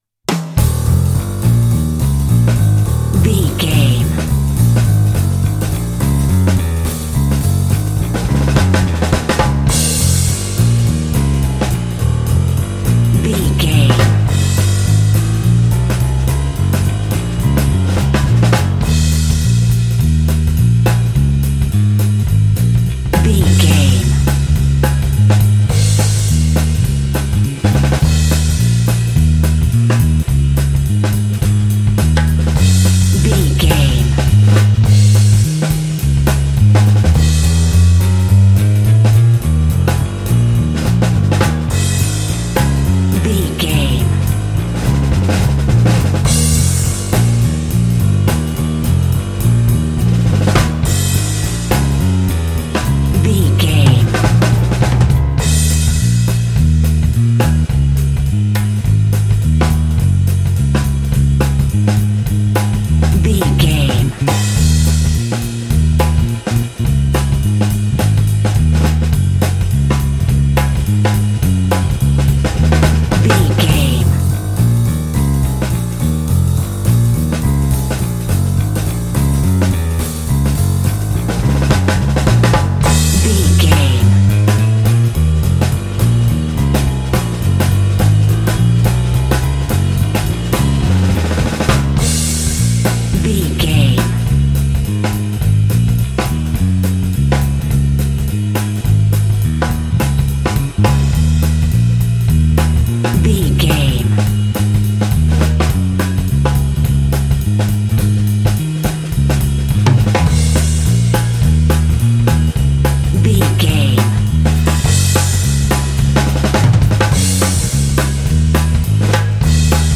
Aeolian/Minor
E♭
energetic
groovy
lively
bass guitar
piano
drums
jazz
big band